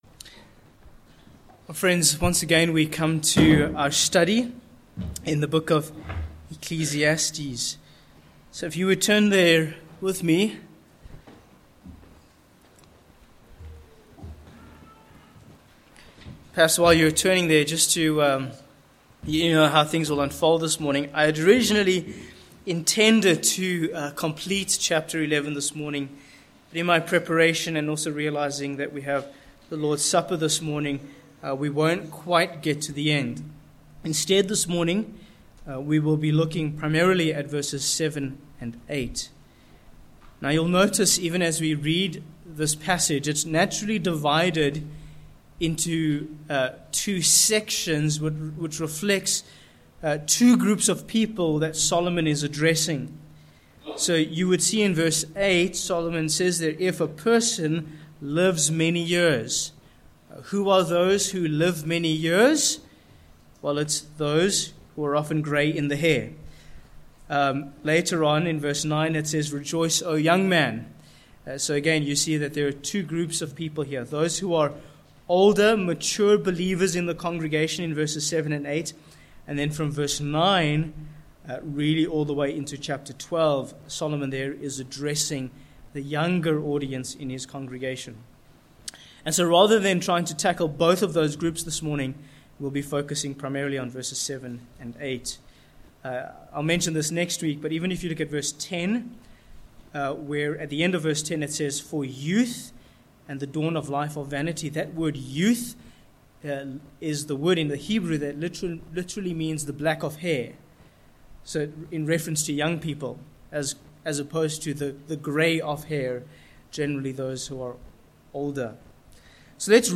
Sermon Points: 1. Rejoice and Remember v7-8
Service Type: Morning